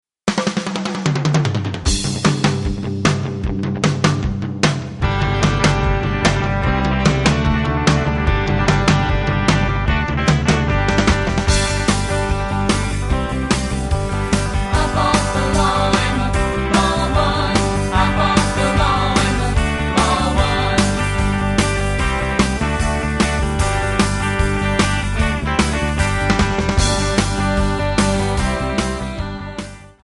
Backing track files: Rock (2136)